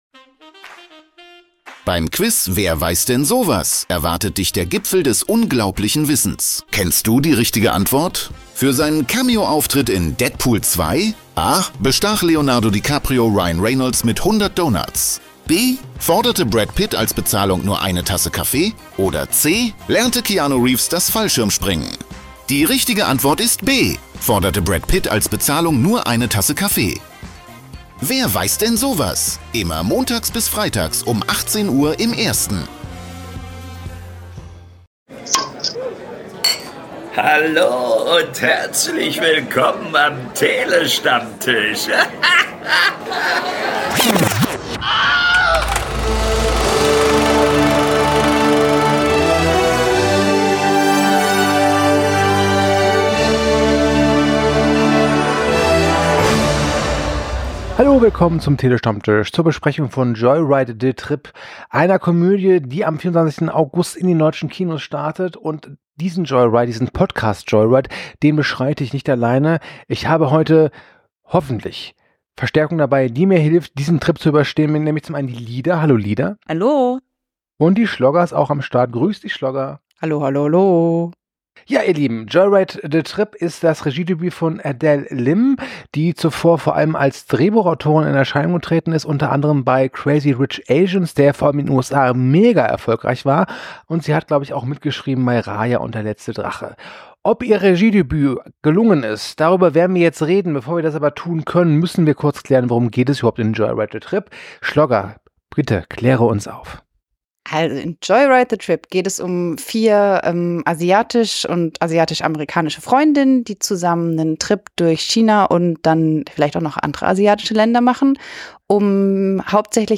Crowd in a bar (LCR).wav
Short Crowd Cheer 2.flac